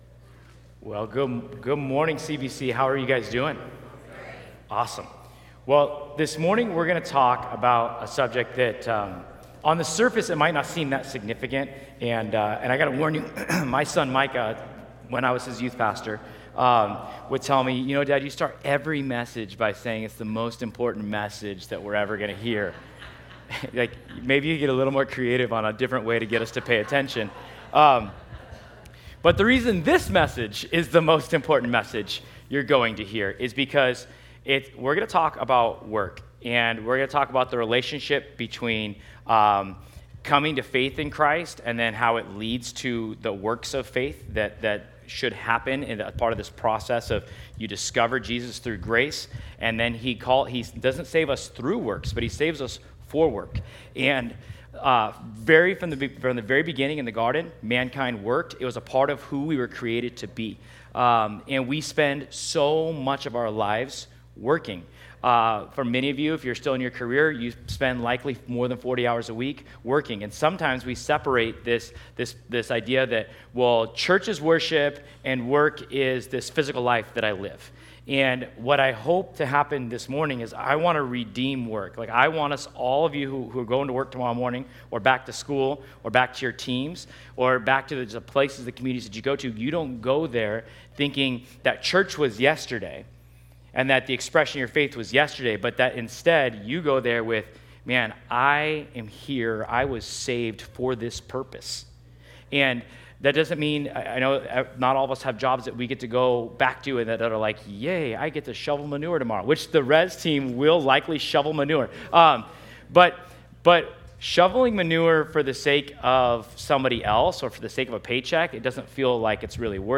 Ephesians 2:8-10 Service Type: Sunday We’re stepping into Week 4 of our series